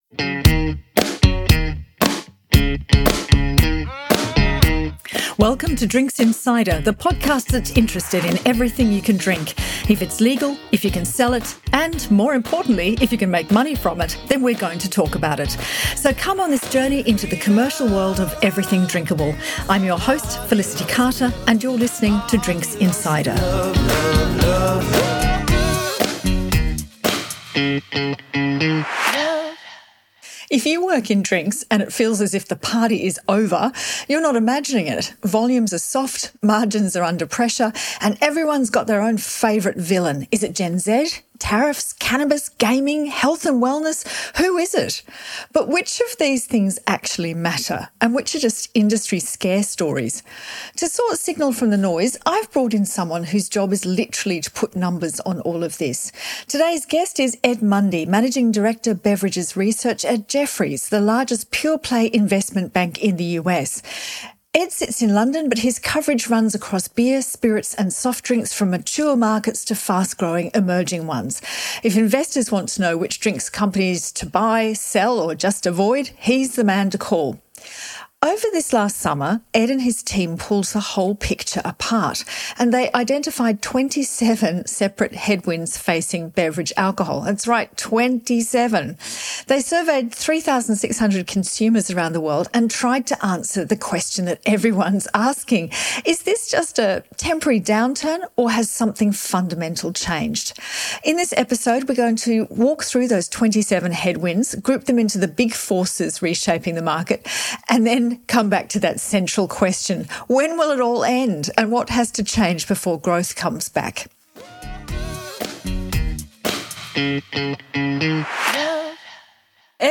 And a big question about whether the industry is doing itself any favours in its approach to marketing and innovation. In this conversation